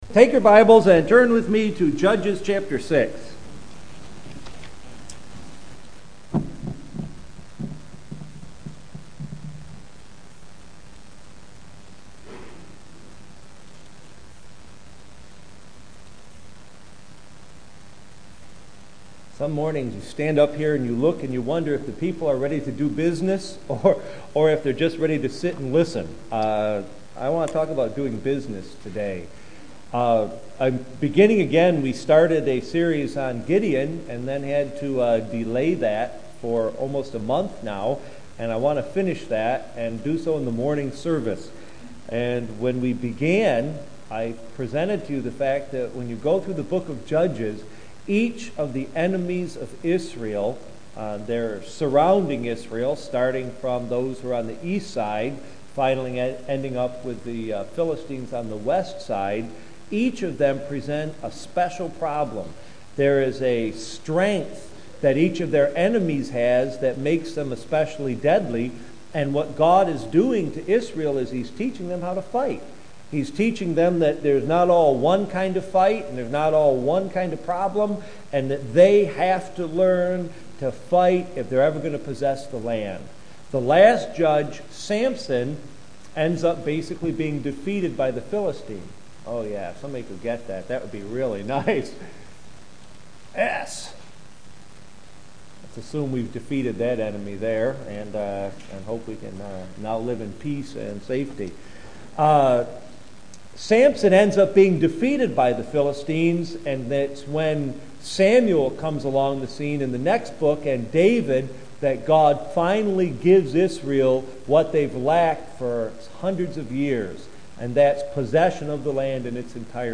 Life of Gideon II – Sermons